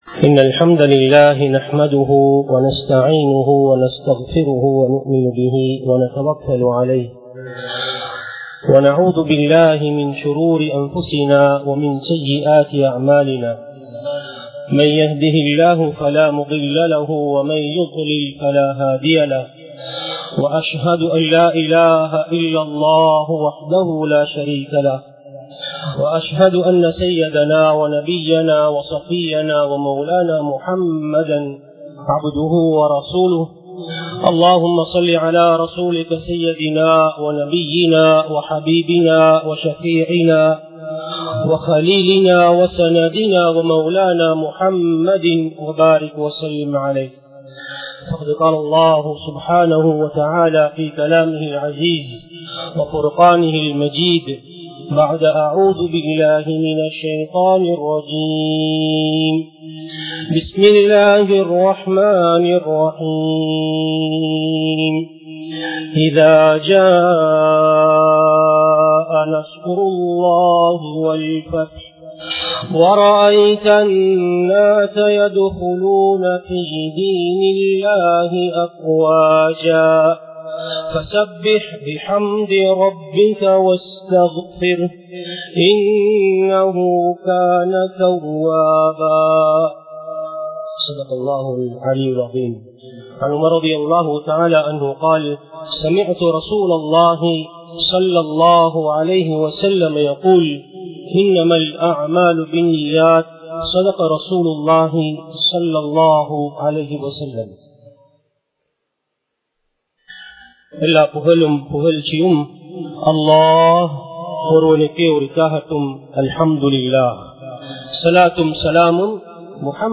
Muhiyadeen Jumua Masjith